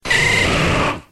Cri de Florizarre dans Pokémon X et Y.